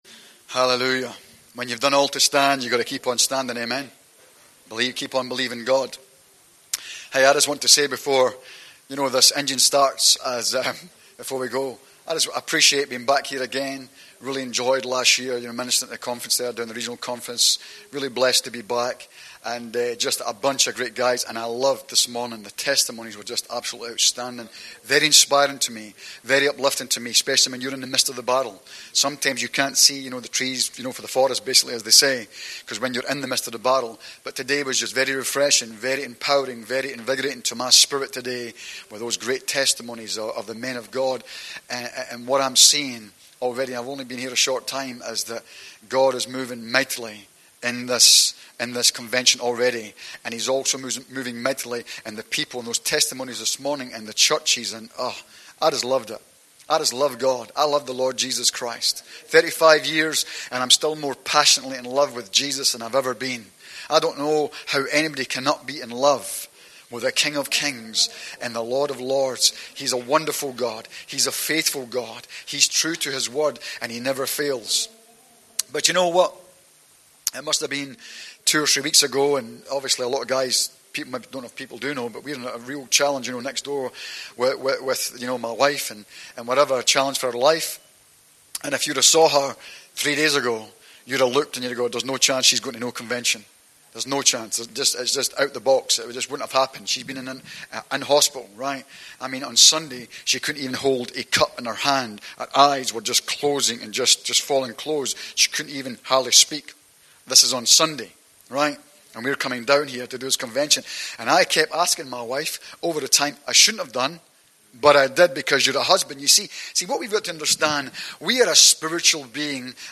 FGBMFI NZ NATIONAL CONVENTION 2017
Men's Seminar
Because of music copyright, only the messages and ministry are recorded